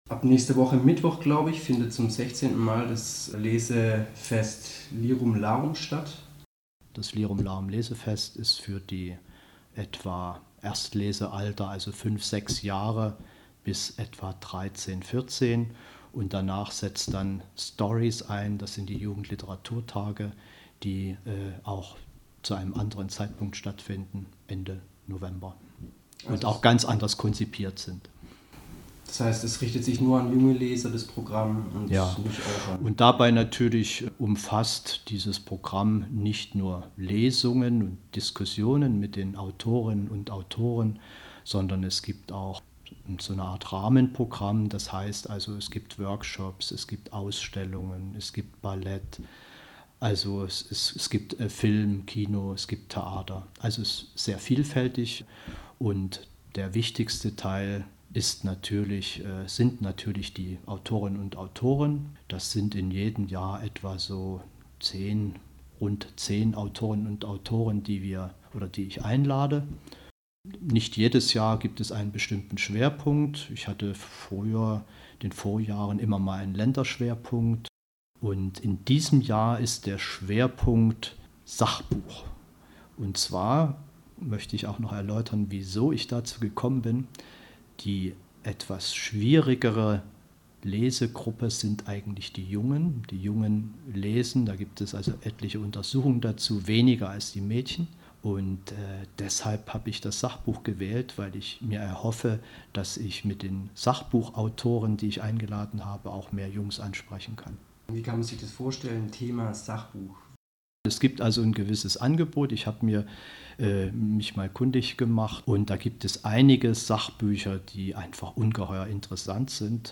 Interview zum Lirum Larum Lesefest vom 30.9. - 6.10.